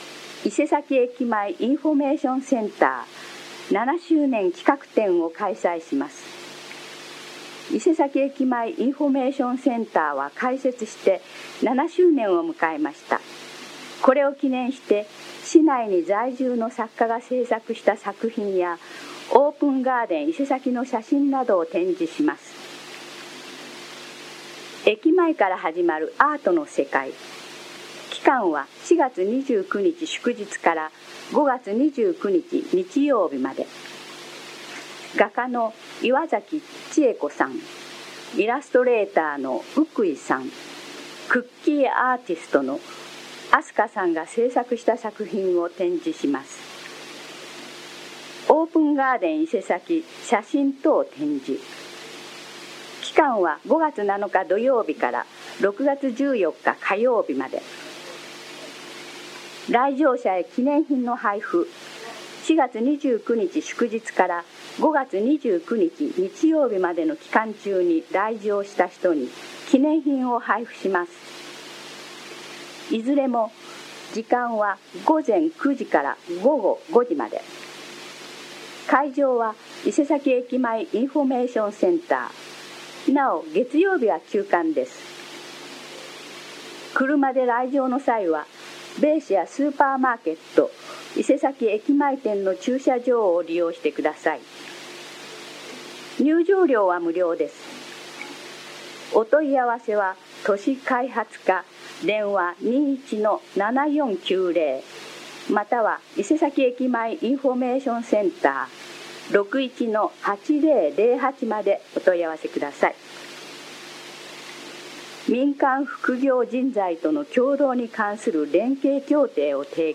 声の広報は目の不自由な人などのために、「広報いせさき」を読み上げたものです。
朗読